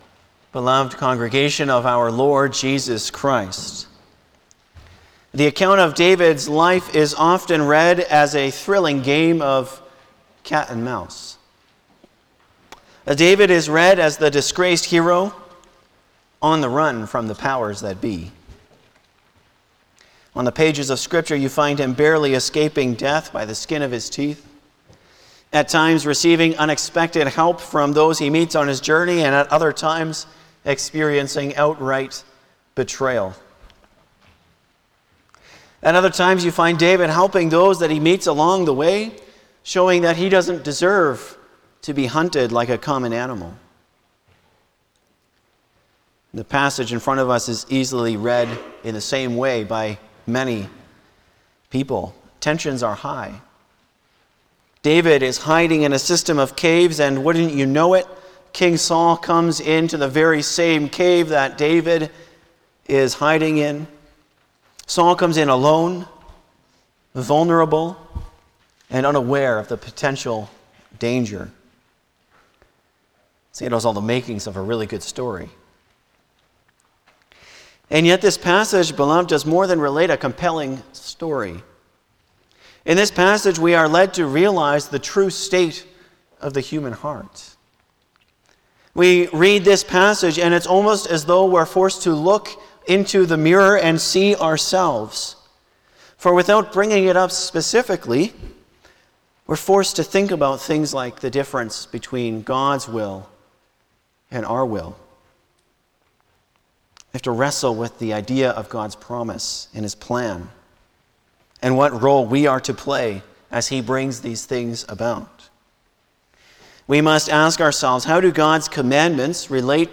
Passage: Lord’s Day 49 Service Type: Sunday afternoon
07-Sermon.mp3